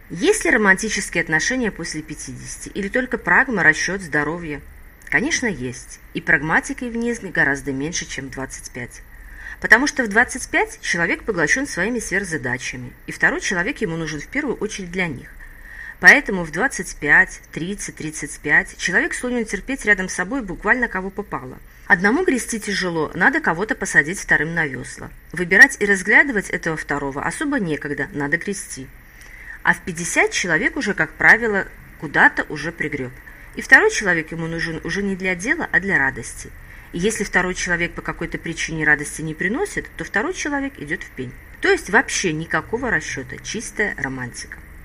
Аудиокнига Пятничные ответы. Многое становится понятным. Том 1 | Библиотека аудиокниг